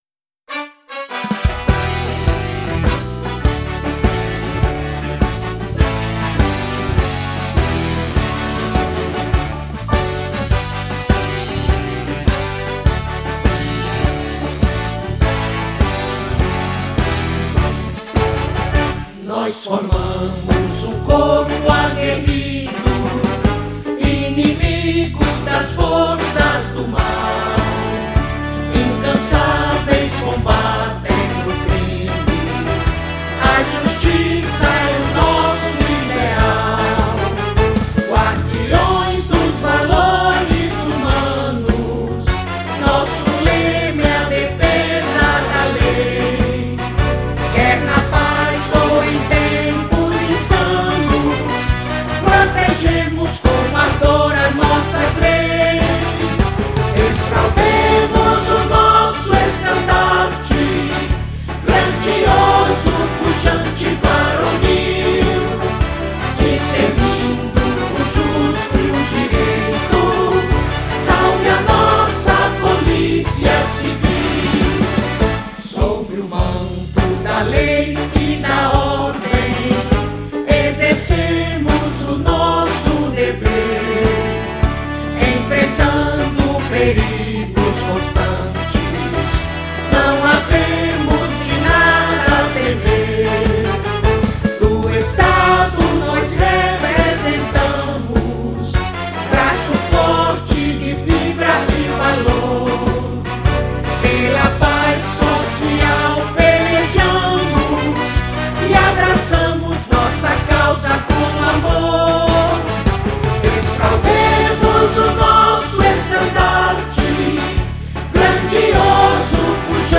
Hino - versão regravada
hino-oficial-policia-civil-pb-regravado-1.mp3